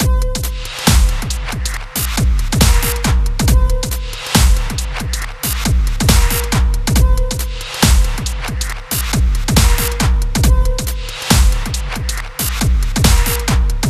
生物震荡的Dubsteppers Loop 138 BPM
在R4中使用3个充满采样的redrums创建的Dubstep循环。
Tag: 138 bpm Dubstep Loops Drum Loops 2.34 MB wav Key : Unknown